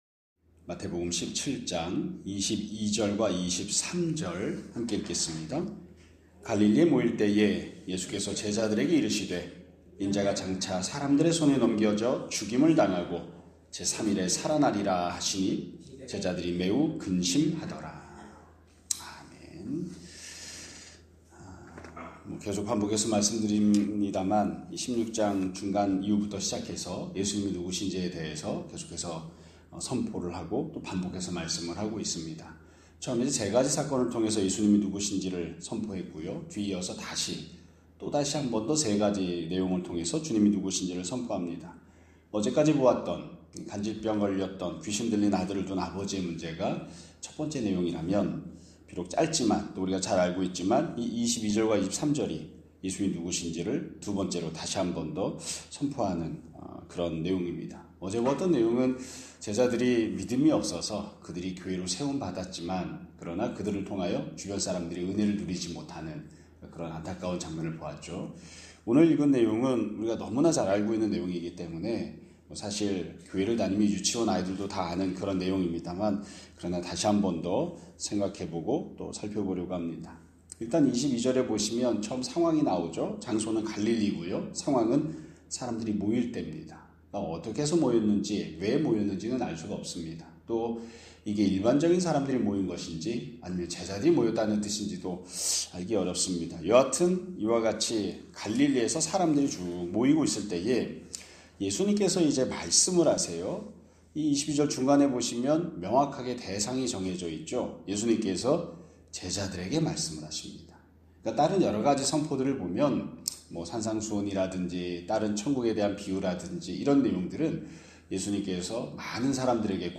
2025년 11월 28일 (금요일) <아침예배> 설교입니다.